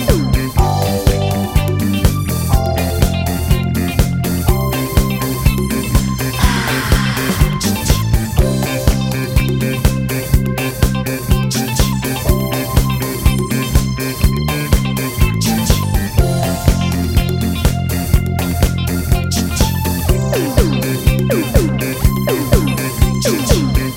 Two Semitones Down Pop (1980s) 4:18 Buy £1.50